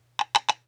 SFX_pasosCaballo1.wav